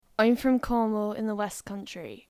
Examples of Cornish English
//ɑɪm frəm ʹkɑɻnwɔːl ɪn ðə wɛst ˈkʌntɻi //
Notice the pronunciation of the first vowel in country. It is /ʌ/ as in RP.